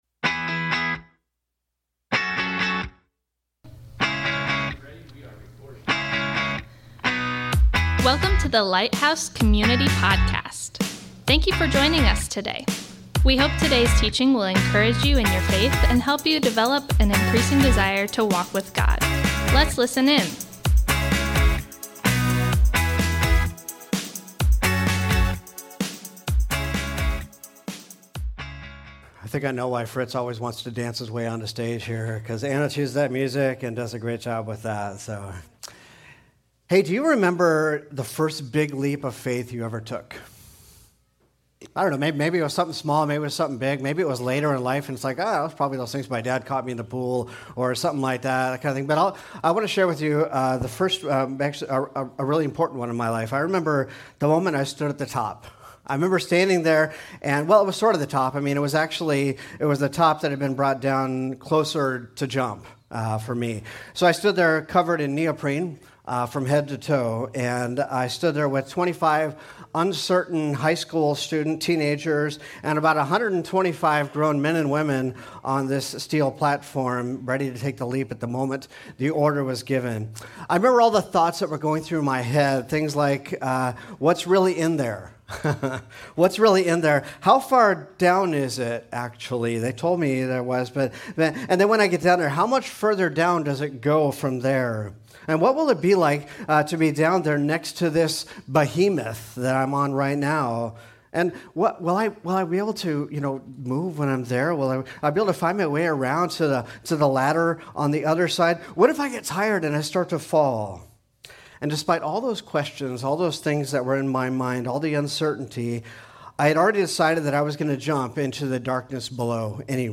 Thanks for joining us today as we worship together.